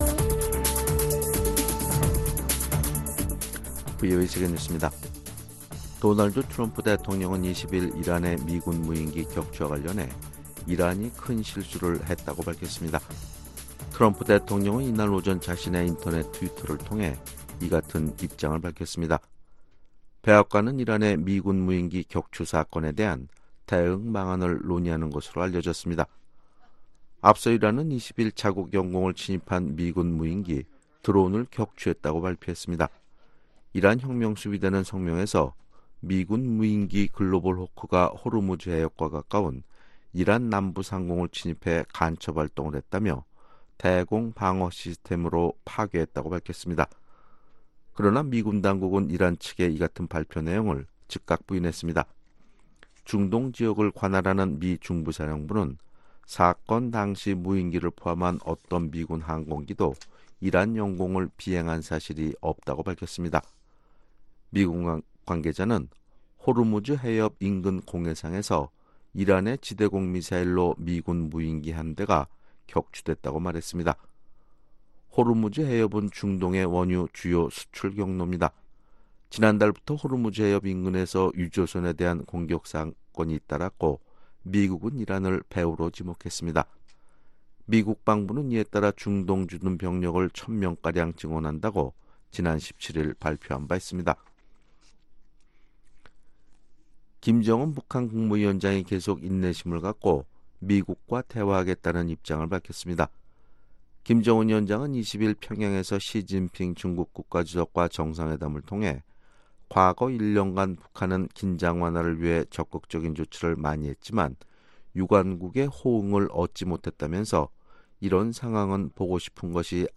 VOA 한국어 아침 뉴스 프로그램 '워싱턴 뉴스 광장' 2019년 6월 21일 방송입니다. 시진핑 중국 국가주석이 오늘(20일) 평양에 도착해 김정은 북한 국무위원장과 정상회담을 하는 등 이틀 간의 국빈방문 일정을 이어가고 있습니다. 미국 재무부가 북한의 제재 회피에 연루된 러시아 금융회사를 전격 제재했습니다.